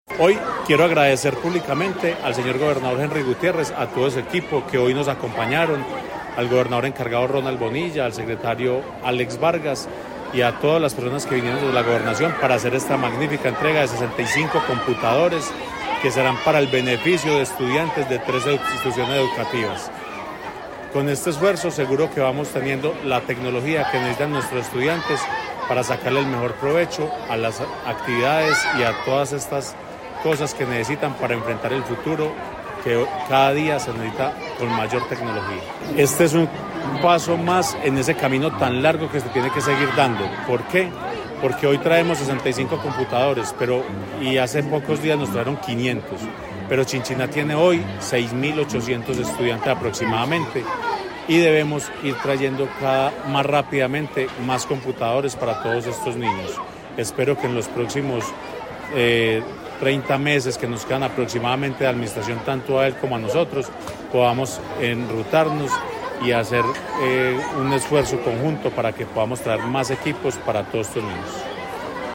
El acto de entrega se realizó en compañía de la comunidad de la Institución Educativa San Francisco de Paula.
Alcalde de Chinchiná, Carlos Alberto Riveros López.